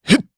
Lusikiel-Vox_Attack1_jp.wav